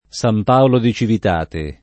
San Paolo [Sam p#olo] top.